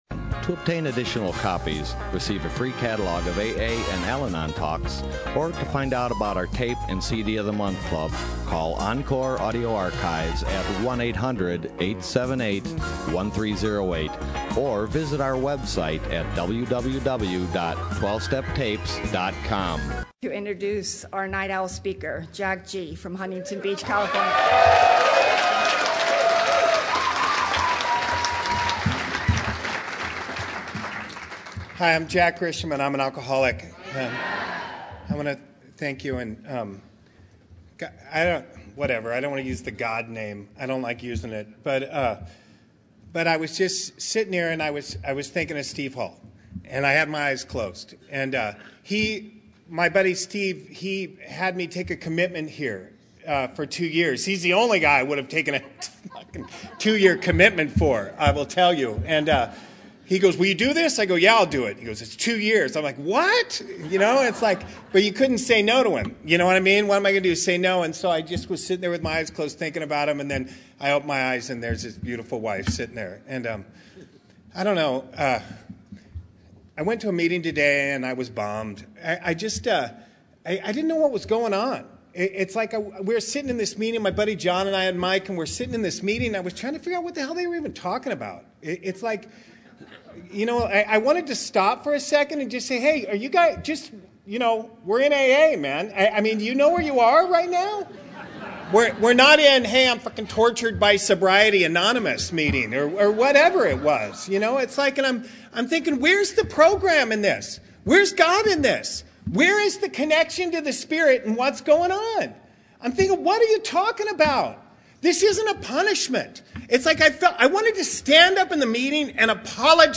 Orange County AA Convention 2015